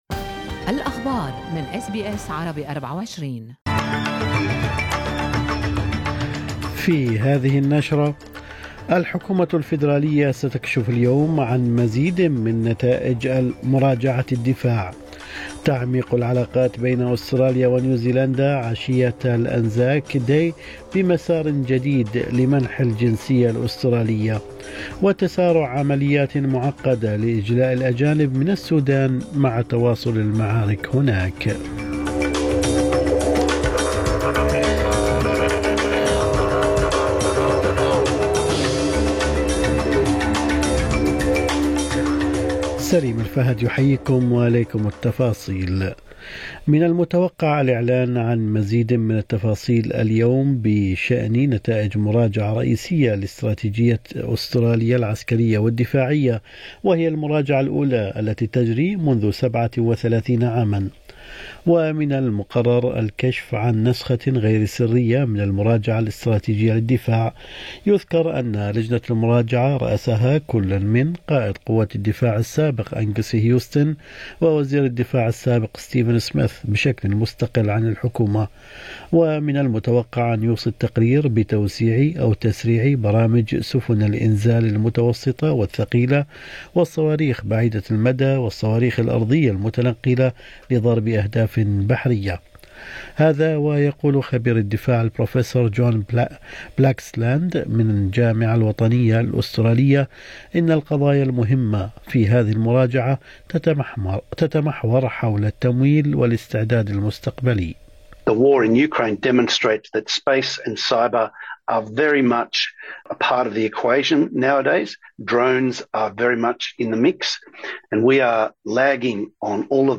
نشرة أخبار الصباح 24/4/2023